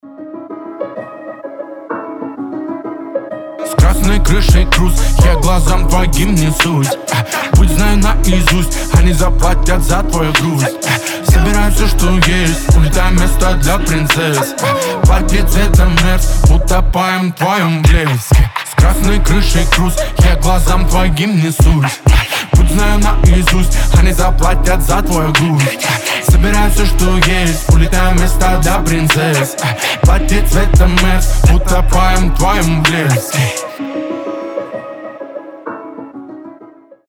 Дуэт
Рэп